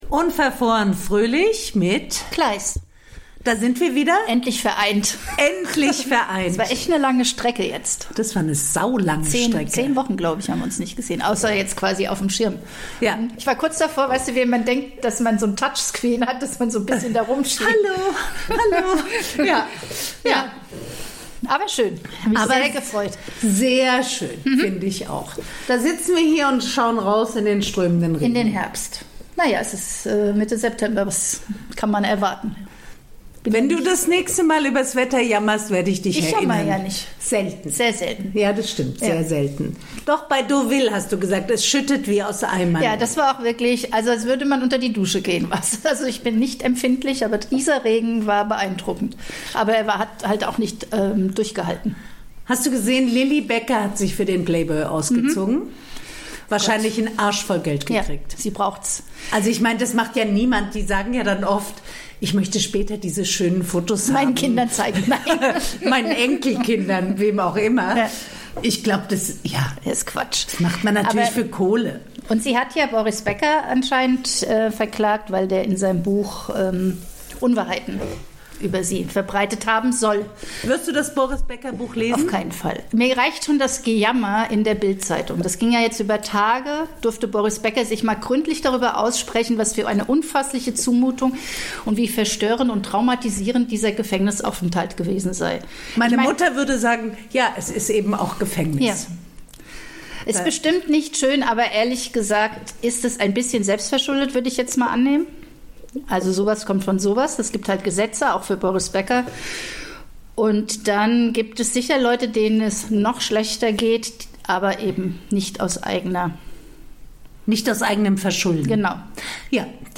reden die beiden Podcasterinnen über unverhoffte Nacktheit, Pickelglamour und ein Debüt.